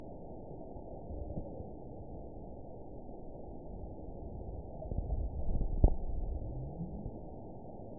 event 917883 date 04/19/23 time 23:00:02 GMT (2 years, 8 months ago) score 8.95 location TSS-AB04 detected by nrw target species NRW annotations +NRW Spectrogram: Frequency (kHz) vs. Time (s) audio not available .wav